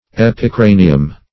Epicranium \Ep`i*cra"ni*um\, n. [NL.